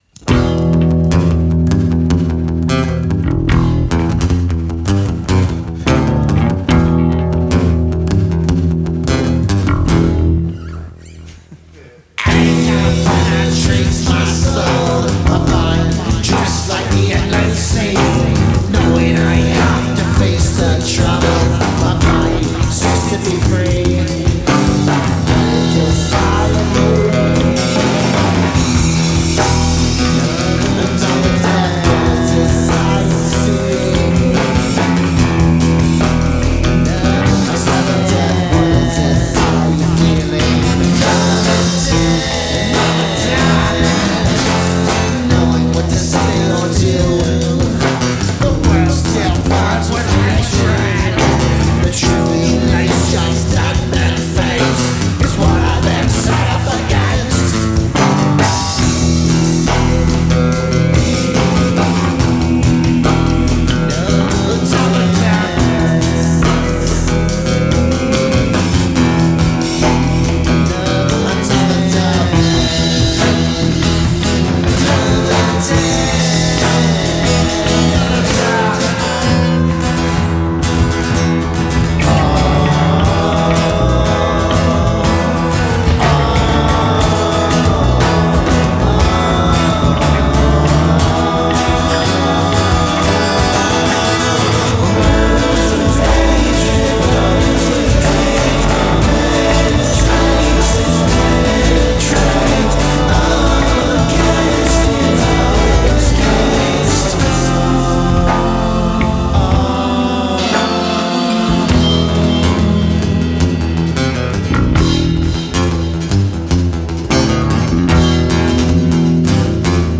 Percussion
Acoustic Guitar
Bass